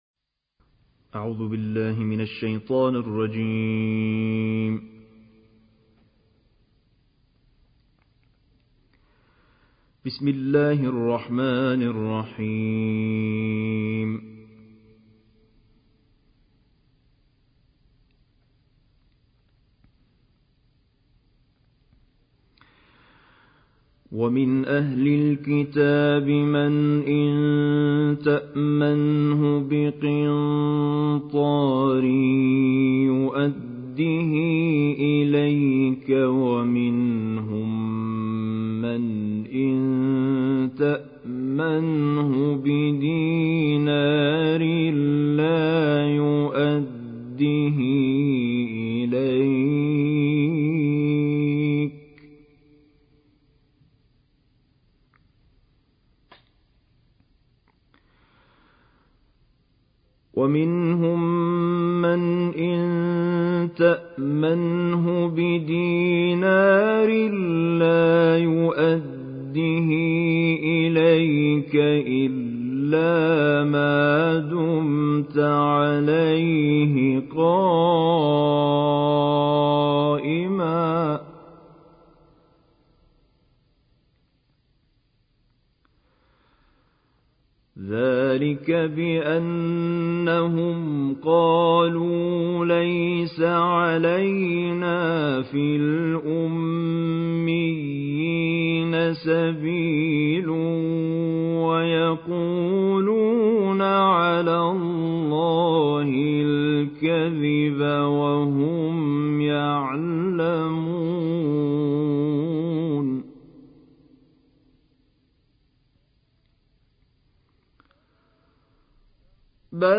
دانلود قرائت سوره آل عمران آیات 75 تا 92